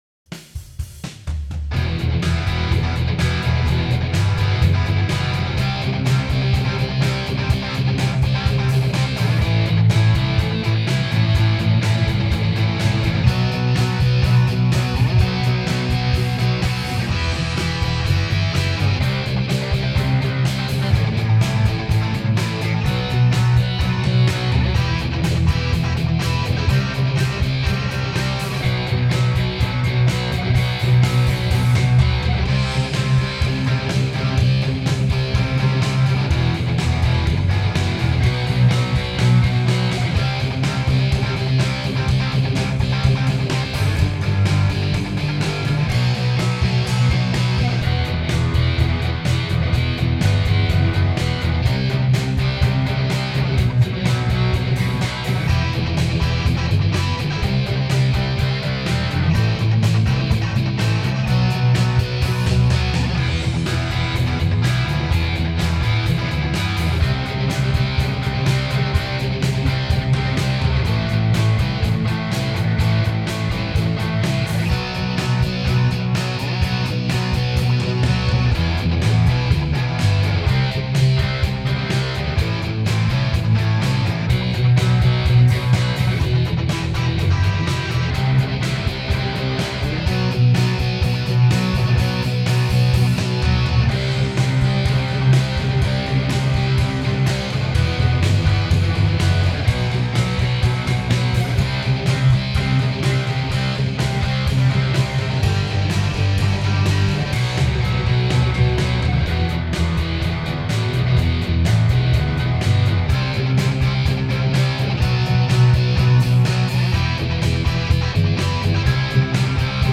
Home > Music > Rock > Running > Chasing > Restless